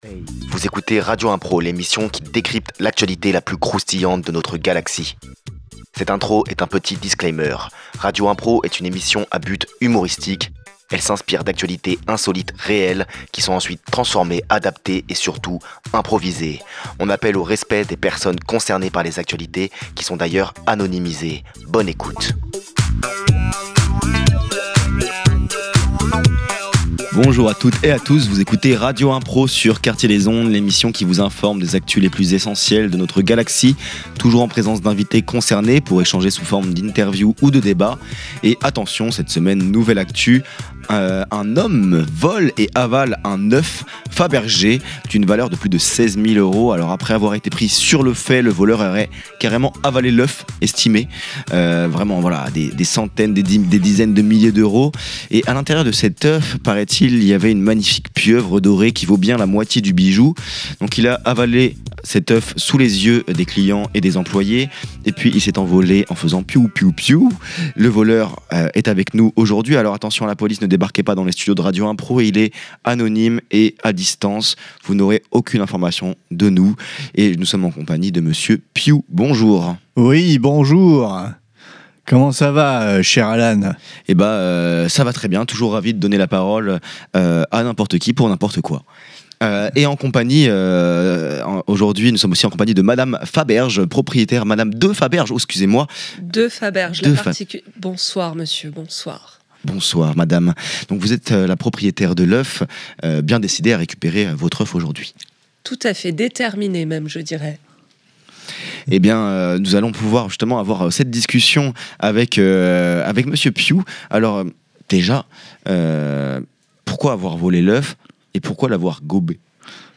Aujourd’hui à radio impro, nous avons le voleur anonyme en visio et Madame de Faberge, propriétaire de l'œuf, bien décidée à la récupérer !